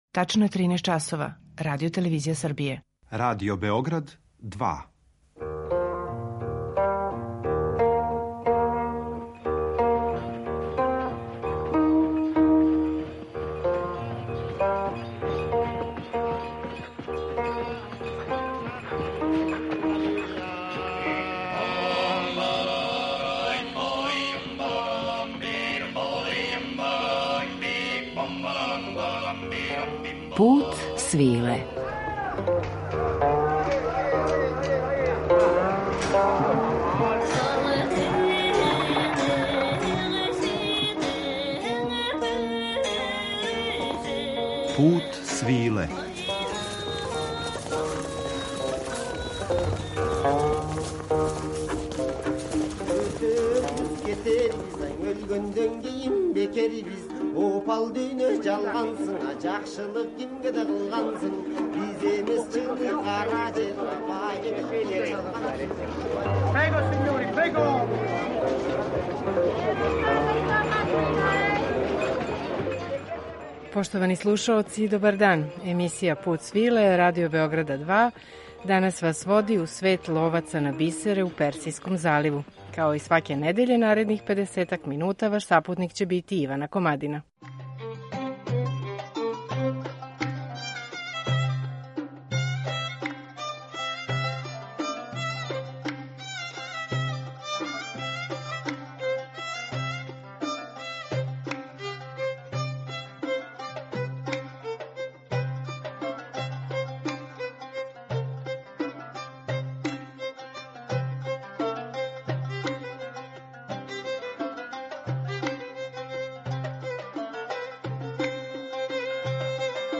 У данашњем Путу свиле откривамо живот и професионалне тајне ловаца на бисере из Персијског залива, као и богат музички репертоар везан уз ову делатност - мелодије из жанра фиђери, које су пратиле сваку фазу њиховог уносног и опасног занимања.